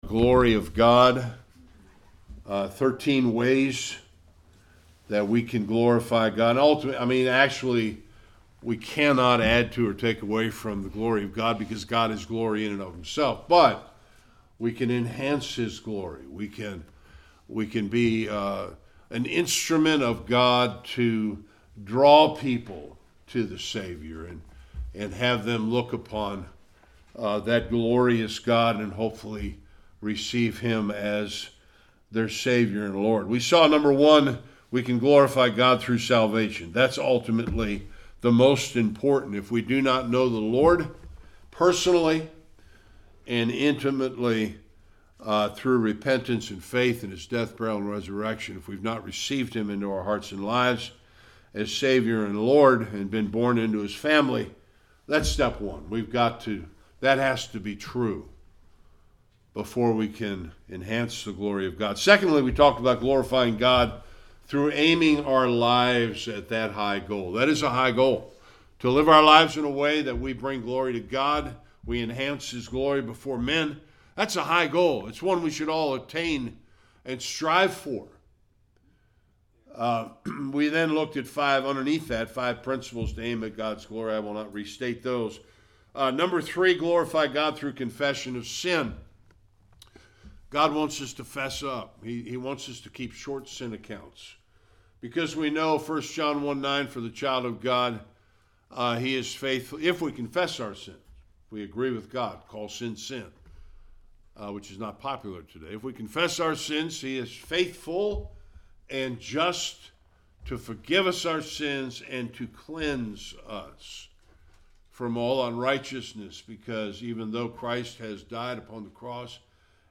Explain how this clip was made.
Various Passages Service Type: Sunday Worship A continuation of 13 ways to enhance God’s glory.